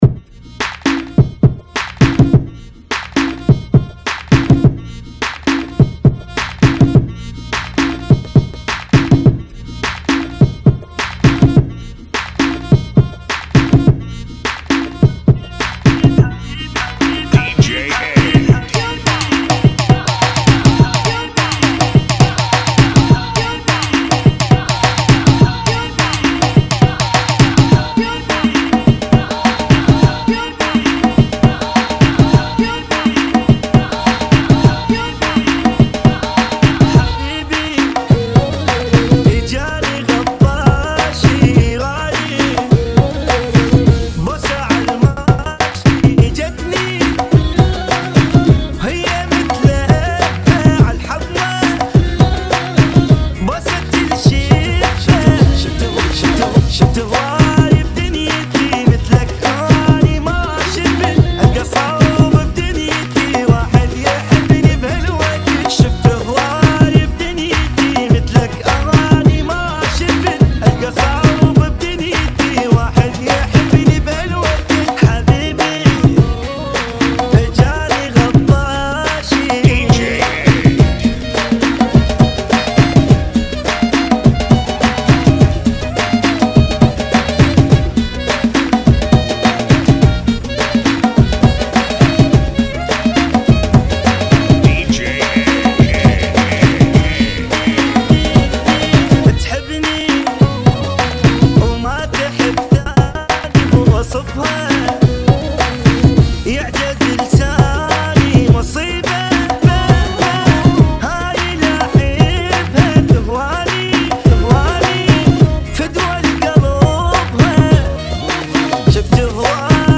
104 Bbm